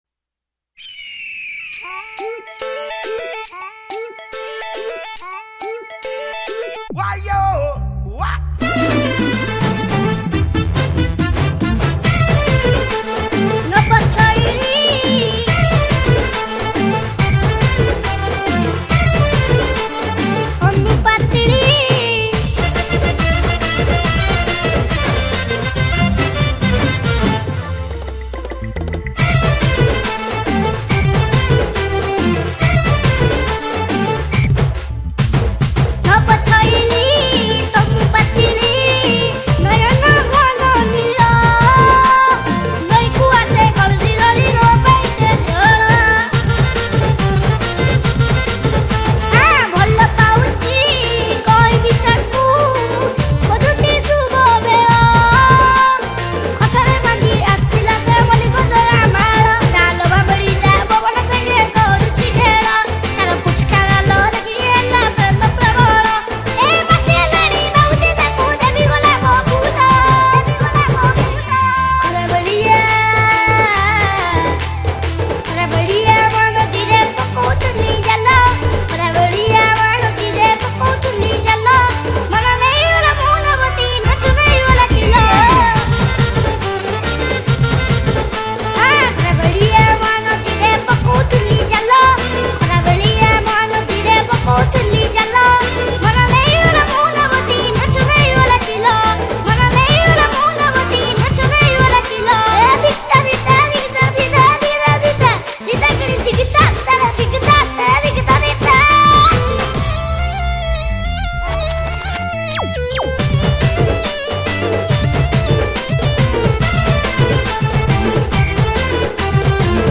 Single Odia Album Song 2025 Songs Download